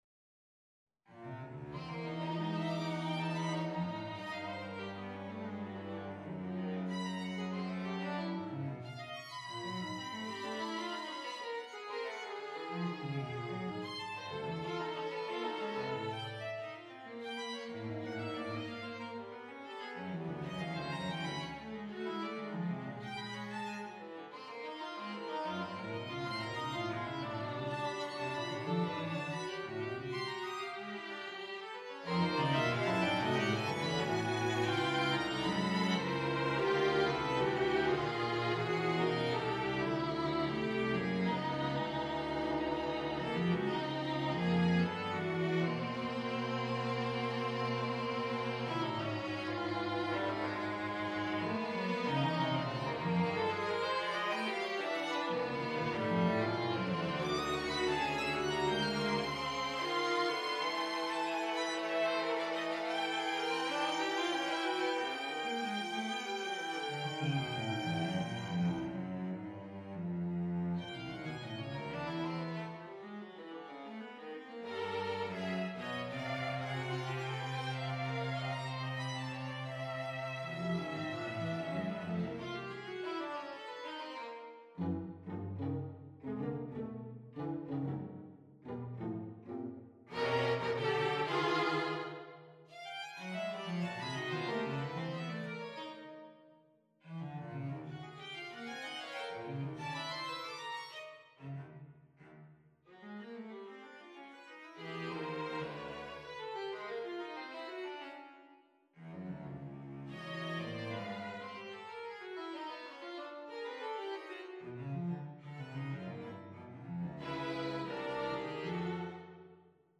for String Quartet
on a purpose-selected tone row
Allegro deciso - Andante con moto - Tempo primo
An even quicker little bagatelle to cleanse the sound palette after the Drang of the 3rd Piano Concerto.